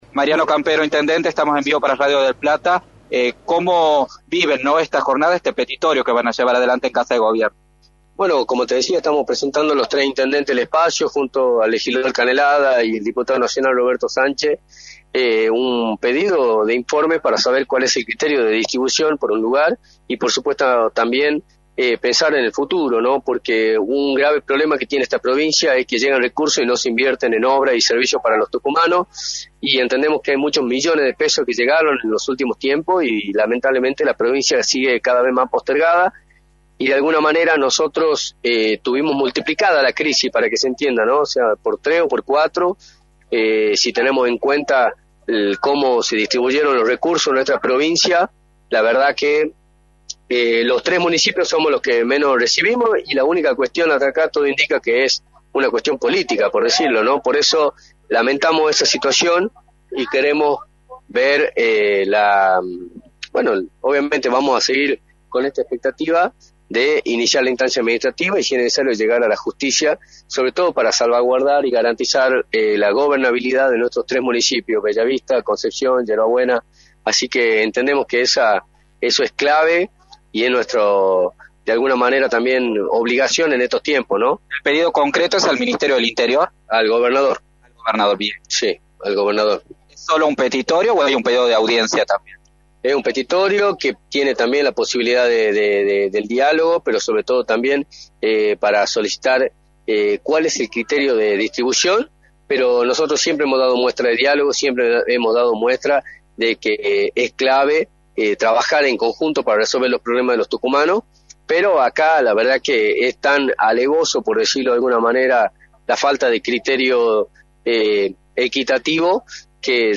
Mariano Campero, Intendente de Yerba Buena y candidato a Diputado Nacional, estuvo presente liderando a la oposición en la presentación del reclamo por la desigualdad en la distribución de recursos y subsidios y analizó en Radio del Plata Tucumán, por la 93.9, la situación de la provincia.
“Un grave problema que tiene esta provincia es que llegan recursos y no se invierten en obra y servicio para los tucumanos, la provincia sigue cada vez más postergada, somos 3 los municipios que menos recibimos y todo indica que es una cuestión política, por eso si es necesario vamos llegar a la justicia para salvaguardar y garantizar la gobernabilidad de nuestros tres municipios, Bellavista, Concepción, Yerba Buena” señaló Mariano Campero en entrevista para “La Mañana del Plata”, por la 93.9.